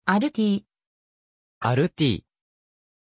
Aussprache von Altti
pronunciation-altti-ja.mp3